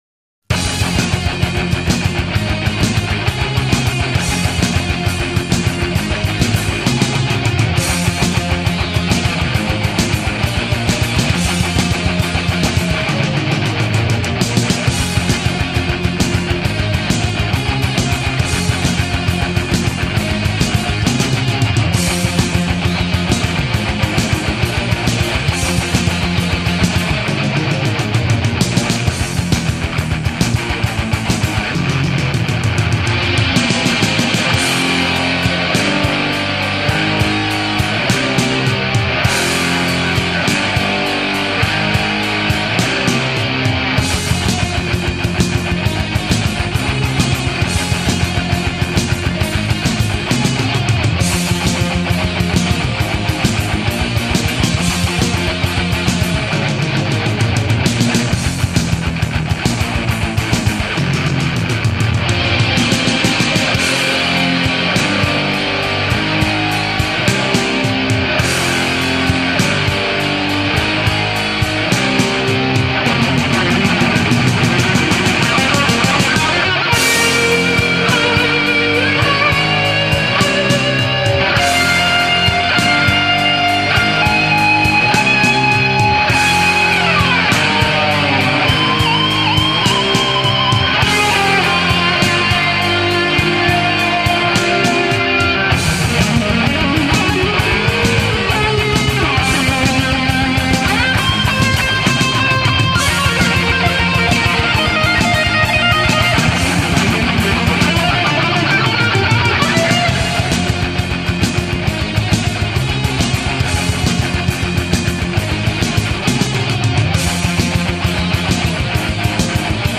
Gothic